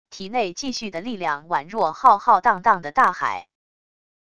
体内继续的力量宛若浩浩荡荡的大海wav音频生成系统WAV Audio Player